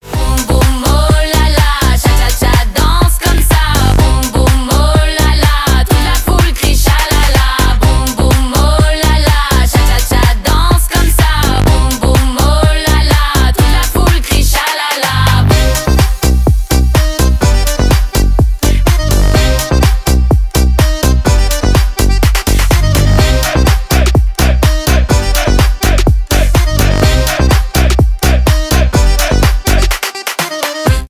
танцевальные
аккордеон